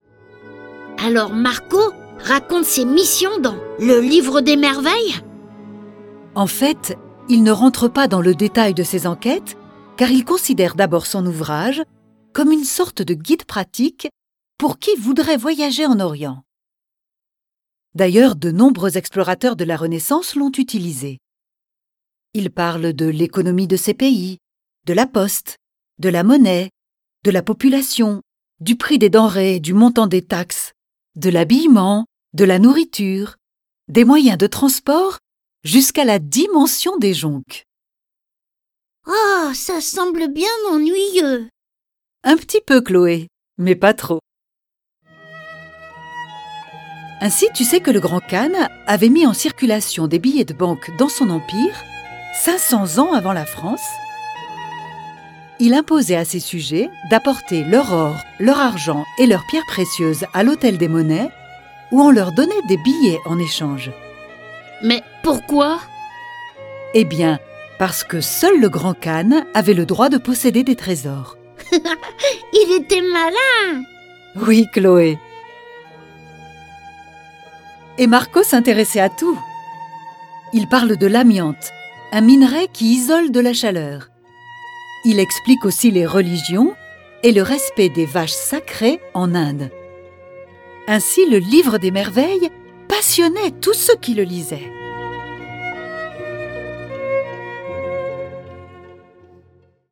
Diffusion distribution ebook et livre audio - Catalogue livres numériques
L'histoire de ce grand voyageur est animé par 7 voix et accompagné de plus de 30 morceaux de musique classique et traditionnelle.